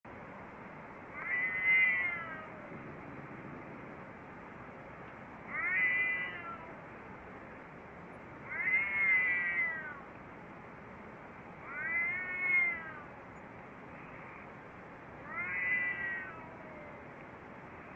Cat Moan Bouton sonore